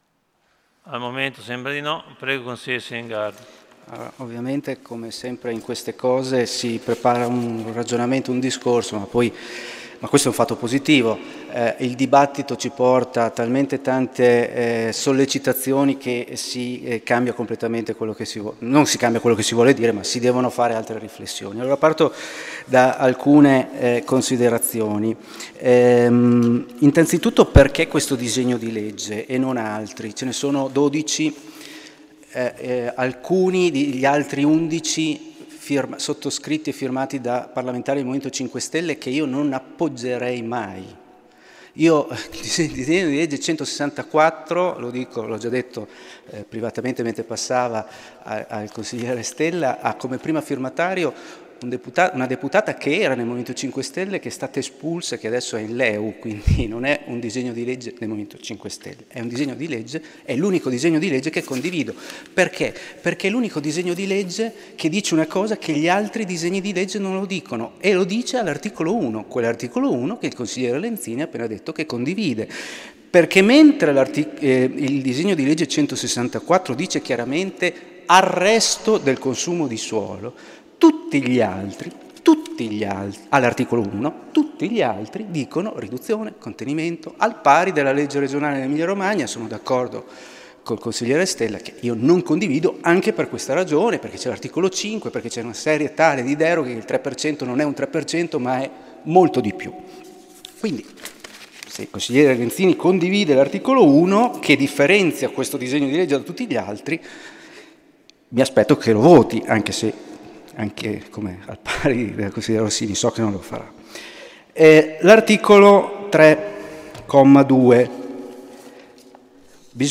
Giovanni Silingardi — Sito Audio Consiglio Comunale